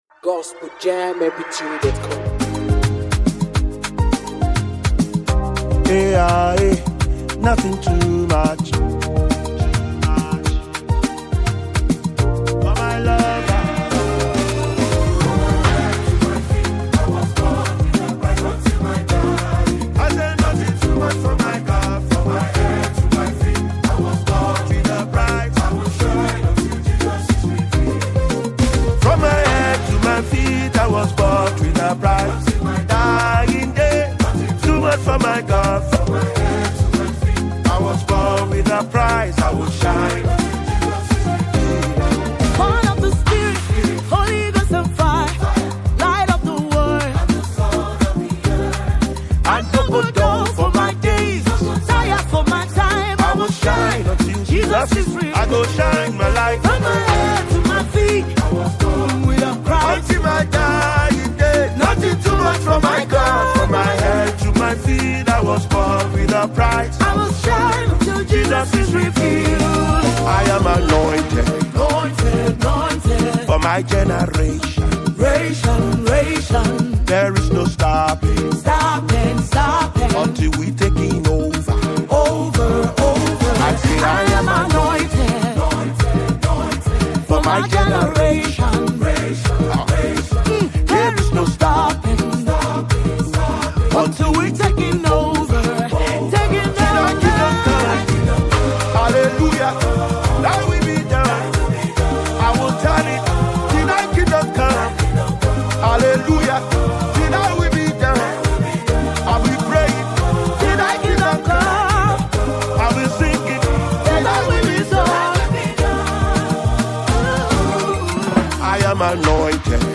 blend contemporary gospel with traditional African rhythms.